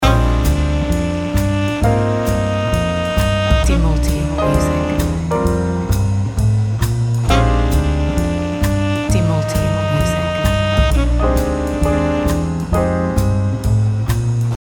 Aransemen glamor yang memberi spotlight pada acara khusus.